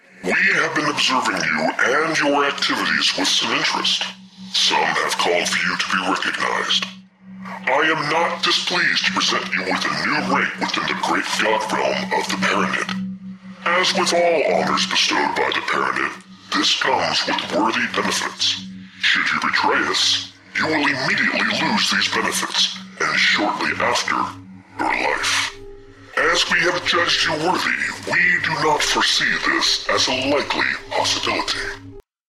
Dialogue Voiceovers
I got promoted to a friend of the GodRealm today in Paranid space, and listened to the voiceover... I had to add my own, so took 10 minutes: